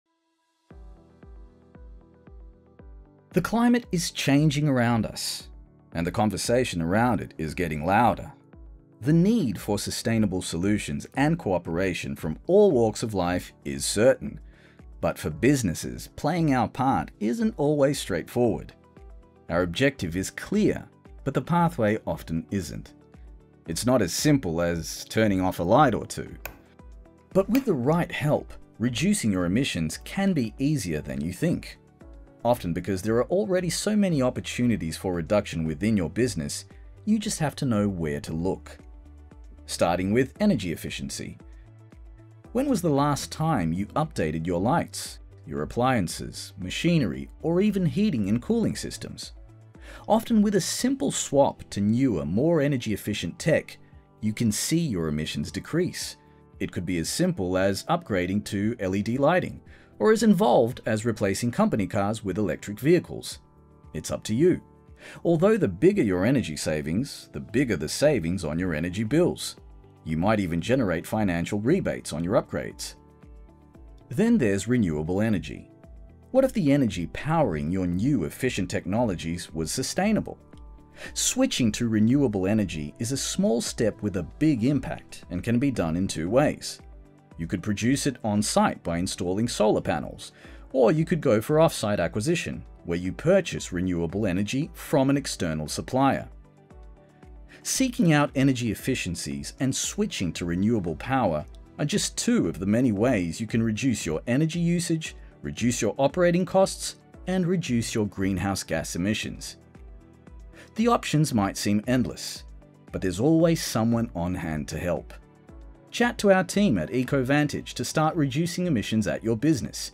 Male
Explainer Videos
Words that describe my voice are Deep, Tenor, Credible.